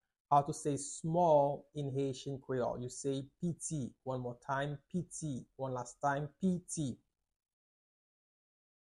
Pronunciation:
Listen to and watch “Piti” audio pronunciation in Haitian Creole by a native Haitian  in the video below:
13.How-to-say-Small-in-Haitian-Creole-–-Piti-pronunciation.mp3